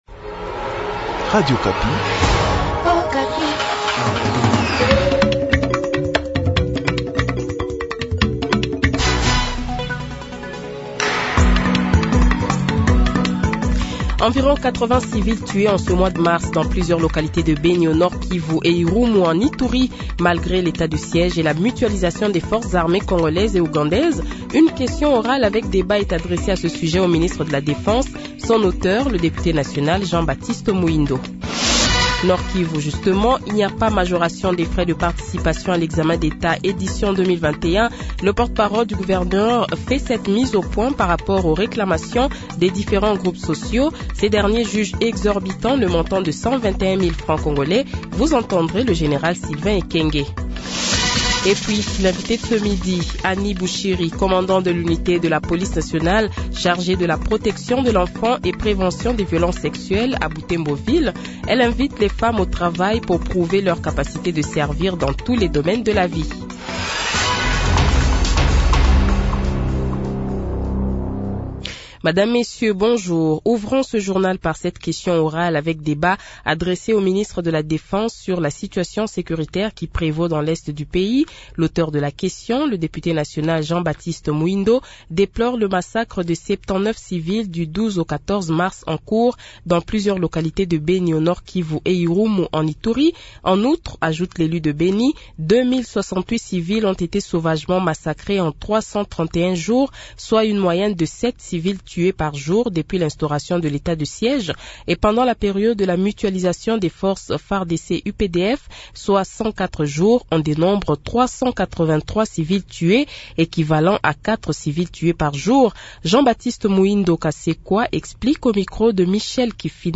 Journal Midi